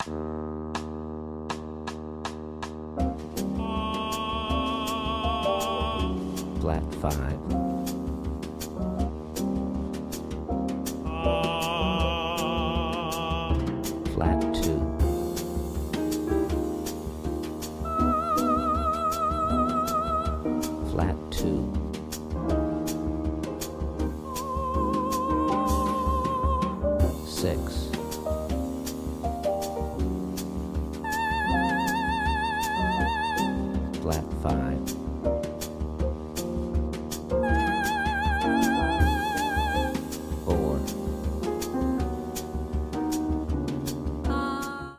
• Listening Exercise with Drone at Fast Tempo